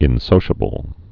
(ĭn-sōshə-bəl)